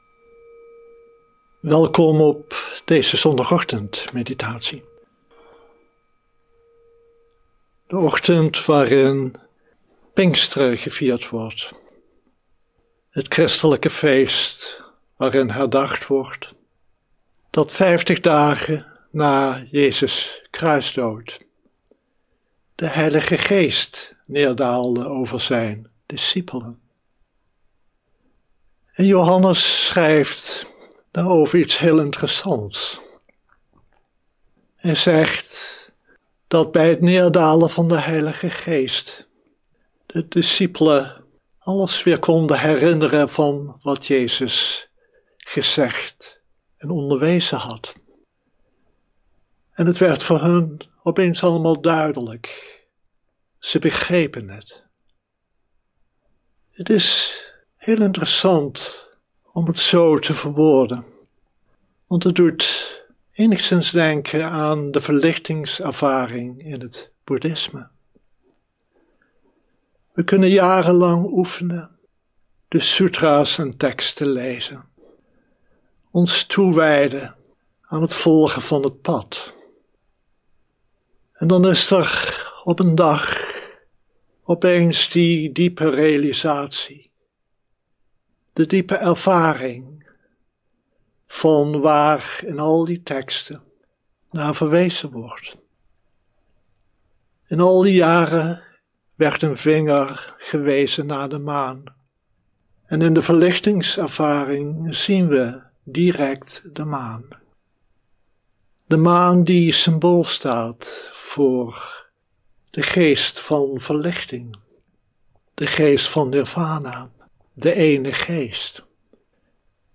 Dharma-onderwijs
Livestream opname